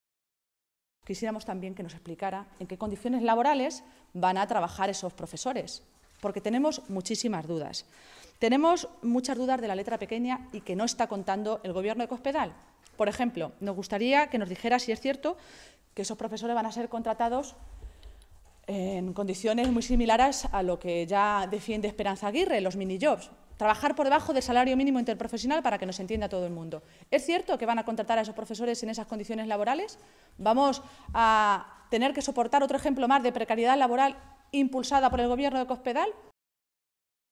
Cristina Maestre, Vicesecretaria y portavoz del PSOE de Castilla-La Mancha
Cortes de audio de la rueda de prensa